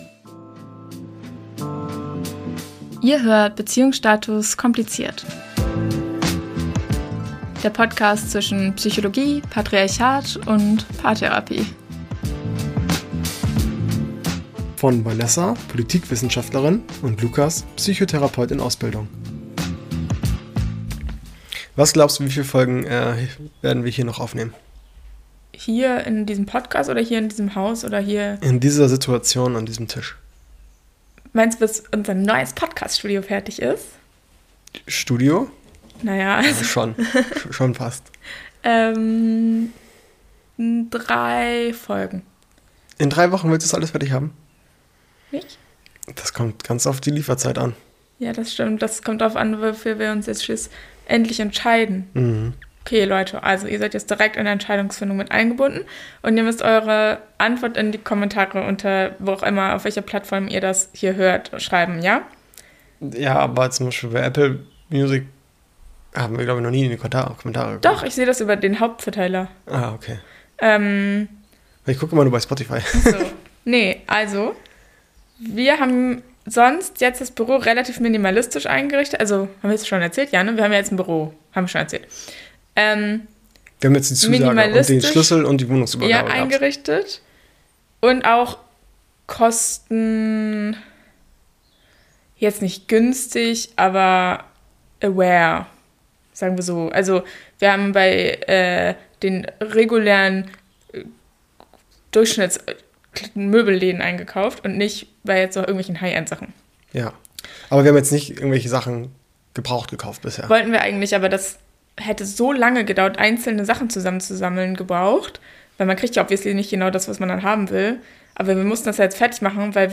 Ein Folge wie aus dem Bilderbuch: wir sind mitten im Büro-Einzugschaos und haben einfach drauf losgesprochen. Es geht um Ikea Besuche, die die Beziehung prüfen, um geschlossene Suchtkliniken und darum, dass es nicht immer das Ende der Welt bedeutet, wenn man sich wie ein A*schloch verhält.